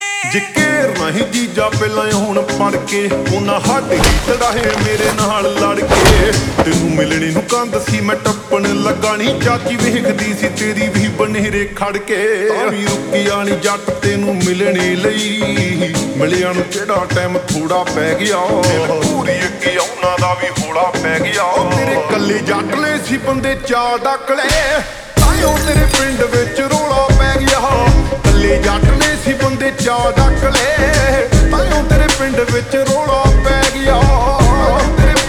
Punjabi Songs
(Slowed + Reverb)